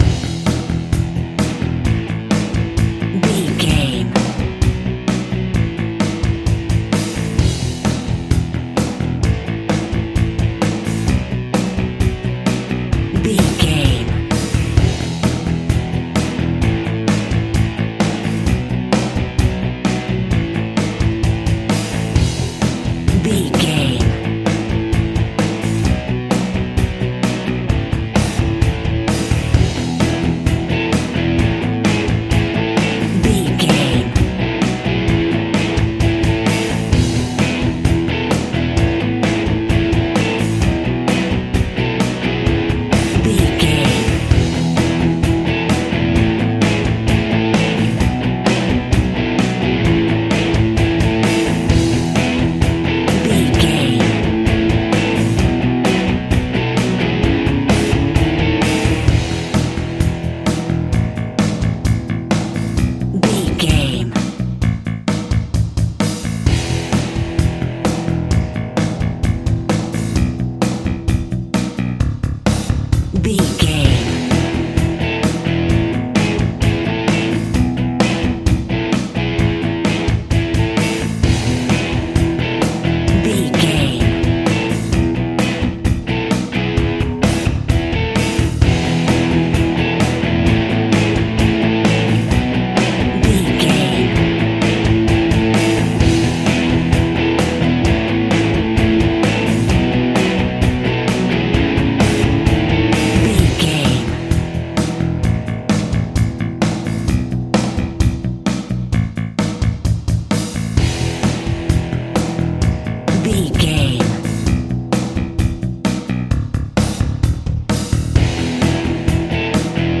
Pop Kids Rocking Out.
Ionian/Major
energetic
driving
heavy
aggressive
electric guitar
bass guitar
drums
pop rock
fun
uplifting
cheesy
synthesizers